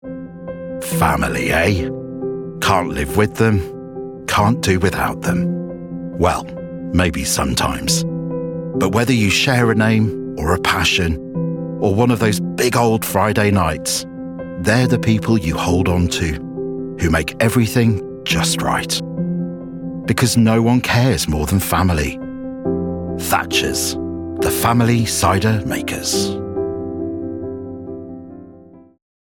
• Male
• London
• Standard English R P
Intimate, Warm, Friendly